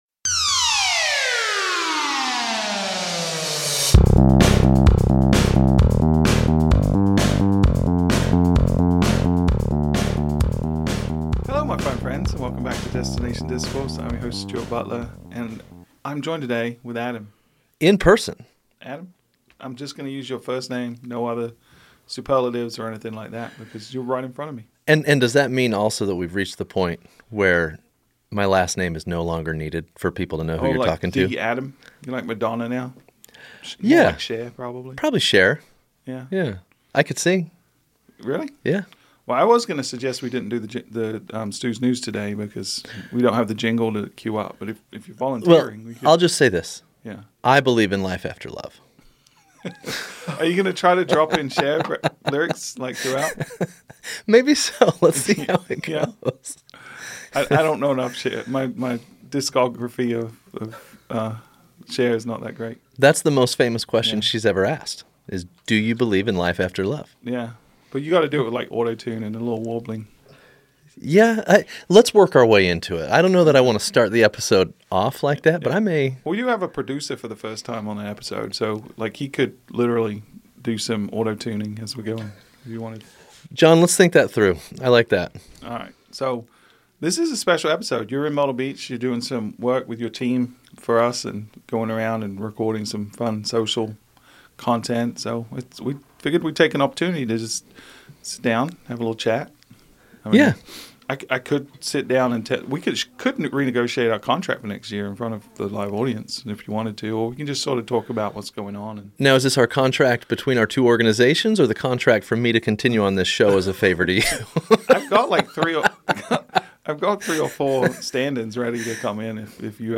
recording live from the Grand Strand